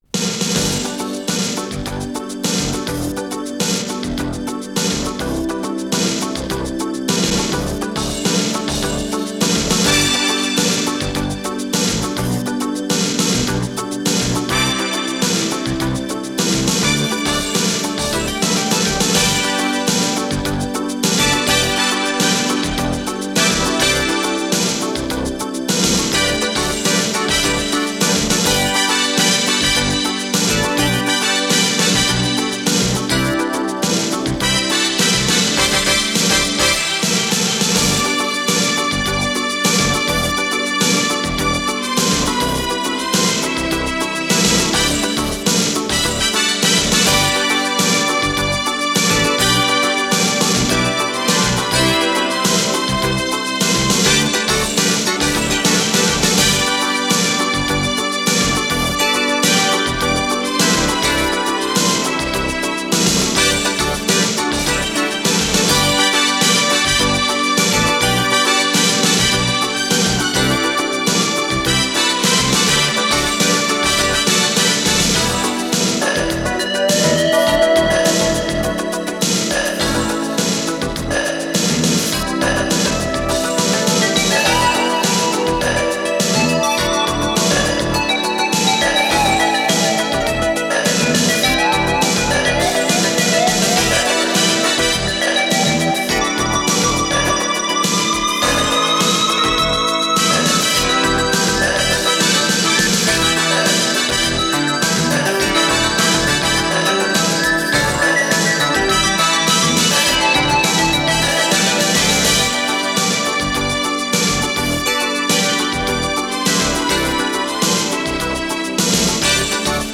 с профессиональной магнитной ленты
Скорость ленты38 см/с
Тип лентыORWO Typ 106
МагнитофонМЭЗ-109А